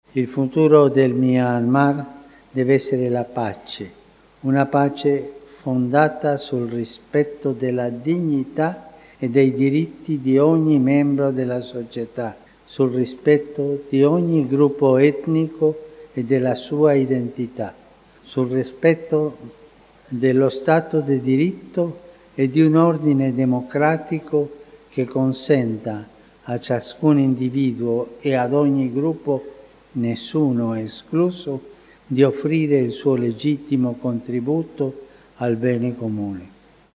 W stolicy tego kraju Ojciec Święty spotkał się z władzami rządowymi, przedstawicielami społeczeństwa obywatelskiego i korpusu dyplomatycznego.
W wygłoszonym przemówieniu Franciszek zaznaczył, że przybył do Mjamy „przede wszystkim po to, aby modlić się z małą, ale żarliwą wspólnotą katolicką tego państwa, aby umocnić ją w wierze i dodać jej otuchy w trudzie wnoszenia swego wkładu w dobro narodu.